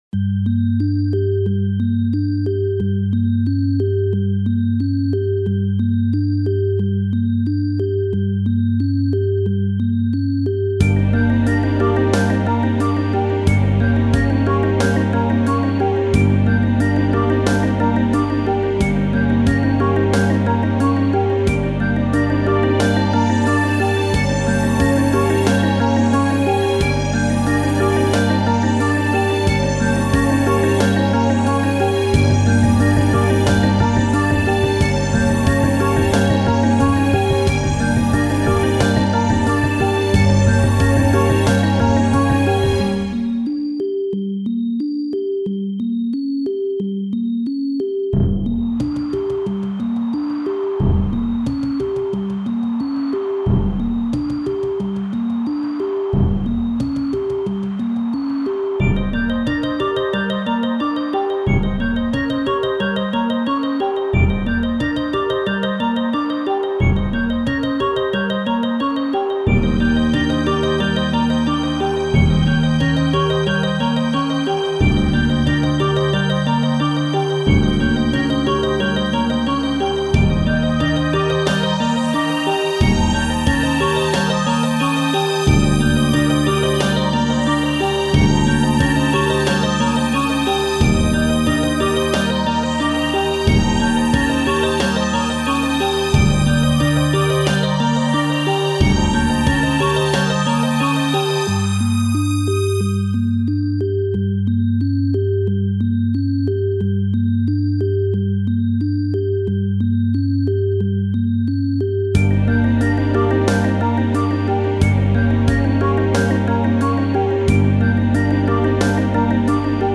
不安 怪しい